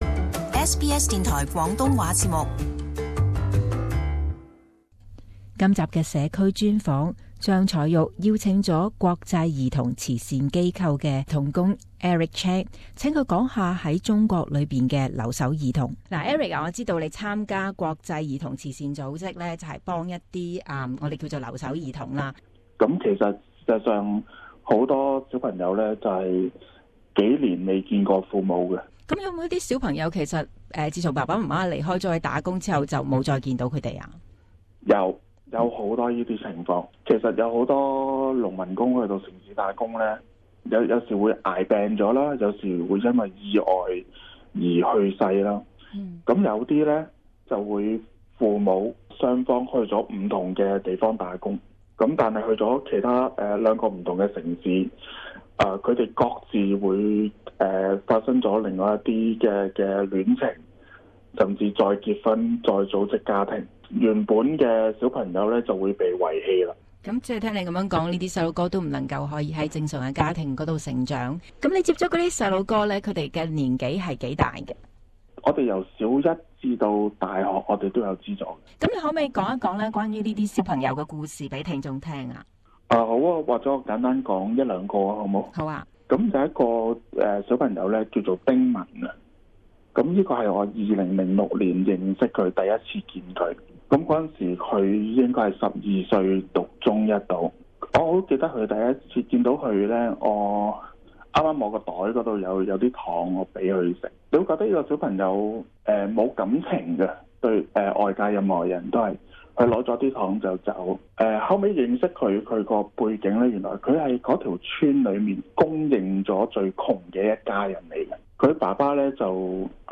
【社區專訪】國際兒童慈善機構